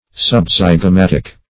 Search Result for " subzygomatic" : The Collaborative International Dictionary of English v.0.48: Subzygomatic \Sub*zyg`o*mat"ic\, a. (Anat.) Situated under the zygoma or zygomatic process.